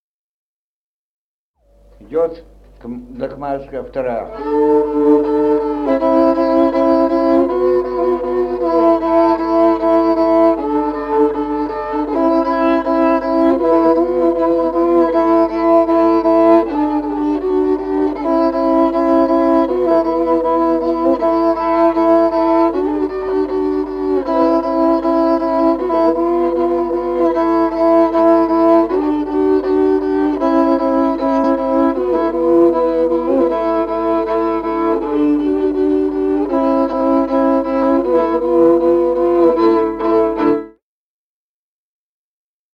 Музыкальный фольклор села Мишковка «Камарицкая», партия 2-й скрипки.